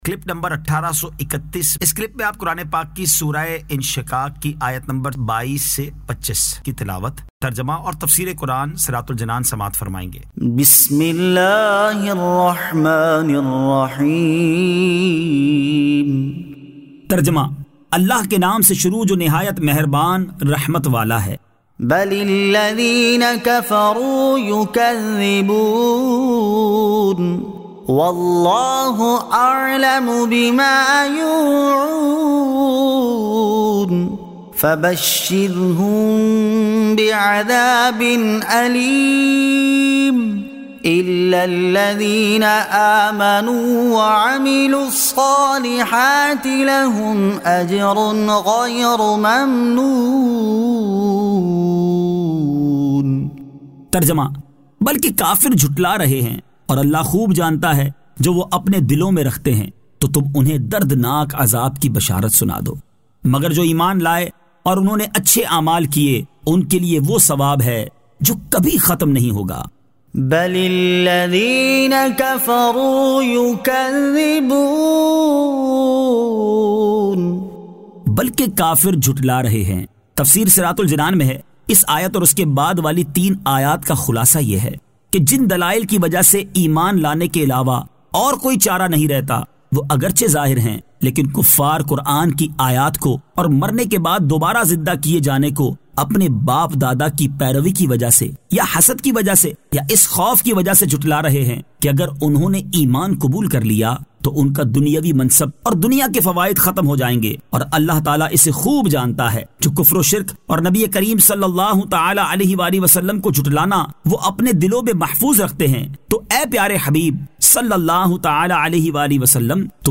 Surah Al-Inshiqaq 22 To 25 Tilawat , Tarjama , Tafseer